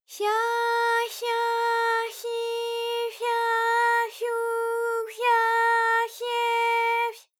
ALYS-DB-001-JPN - First Japanese UTAU vocal library of ALYS.
fya_fya_fyi_fya_fyu_fya_fye_fy.wav